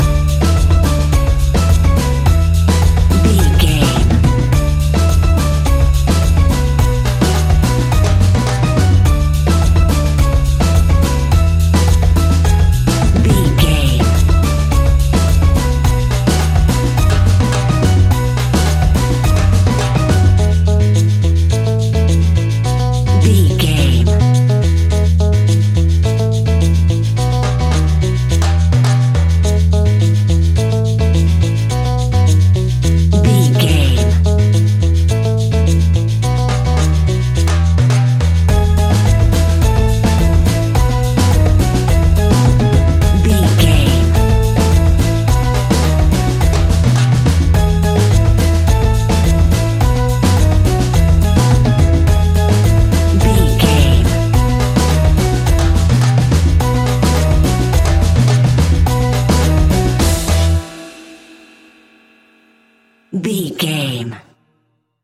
Ionian/Major
steelpan
calypso music
drums
percussion
bass
brass
guitar